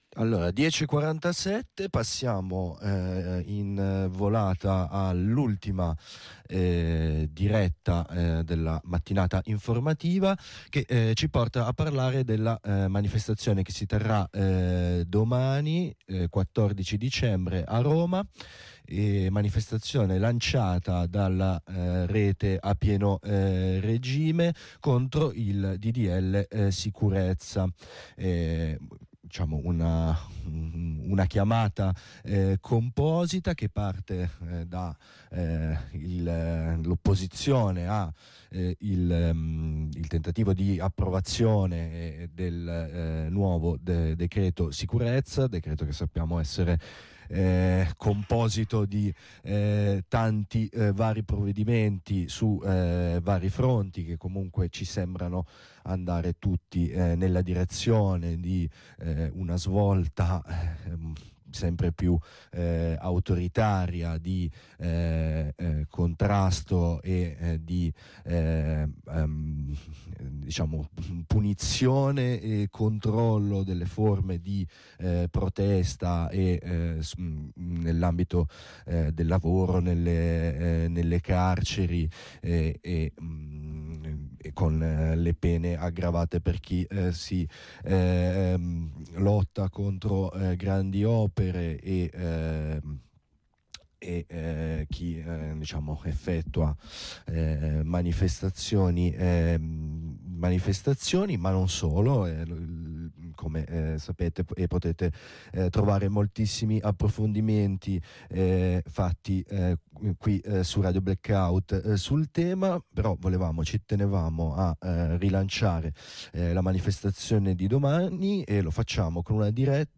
Ai nostri microfoni